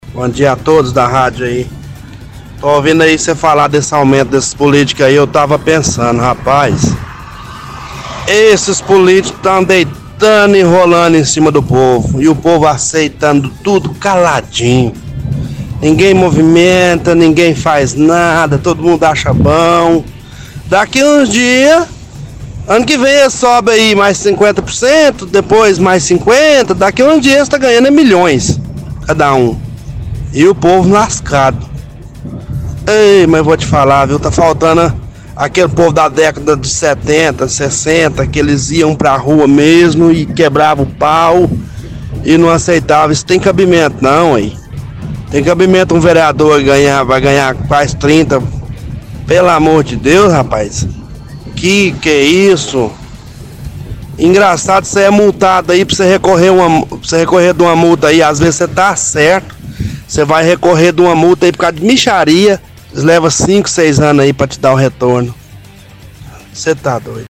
-Ouvinte reclama do aumento do salário dos vereadores para quase 30 mil reais, diz que é engraçado quando a gente é multado e vai recorrer uma multa mesmo estando certo demora 5 a 6 anos para te dar um retorno.